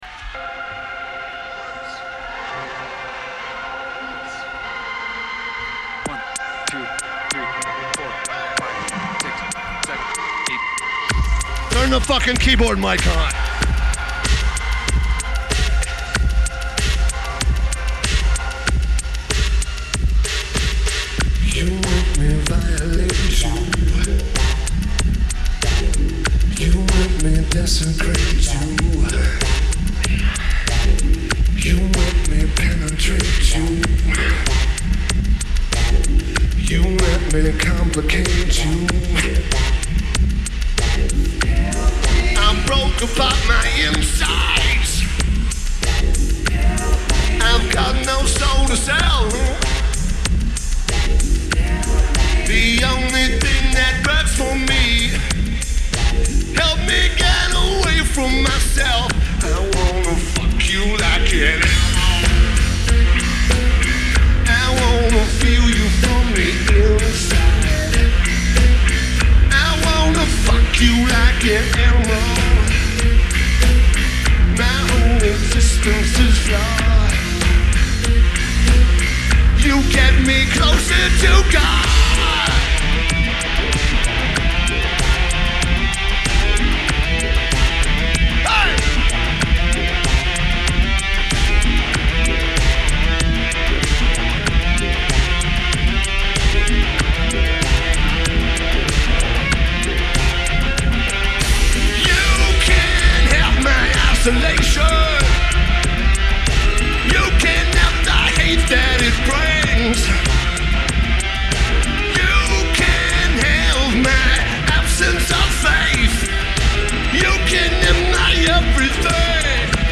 Summer Sonic Festival
Drums
Bass
Guitar
Vocals/Guitar/Keyboards